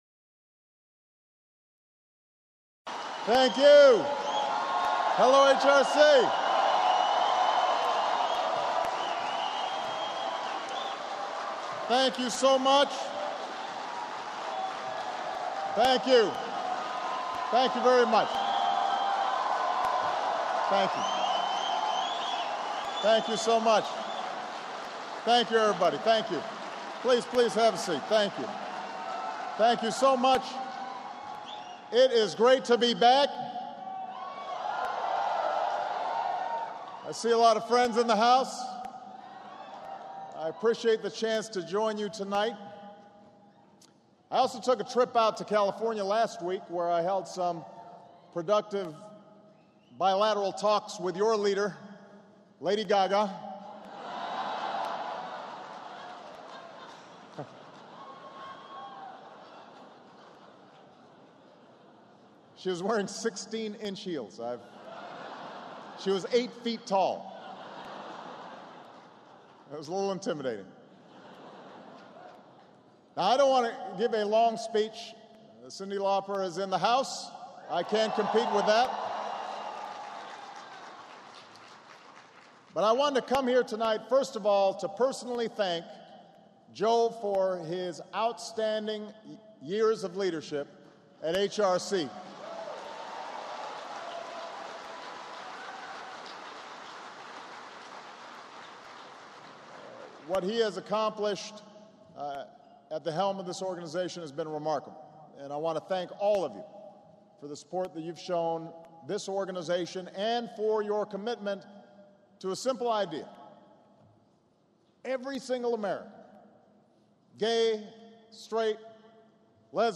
President Barack Obama speaks at the Human Rights Campaign's 15th Annual National Dinner held at the Washington Convention Center.
Recorded at the Washington Convention Center, D.C., Oct. 1, 2011.